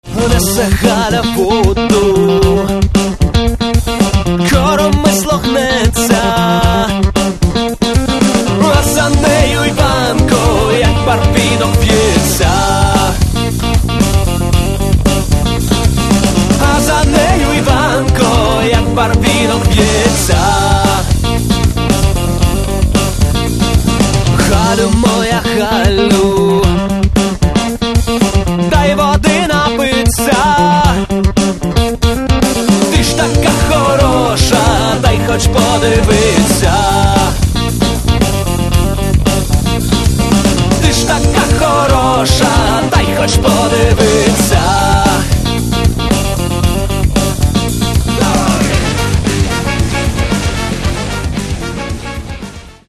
Catalogue -> Rock & Alternative -> Folk Rock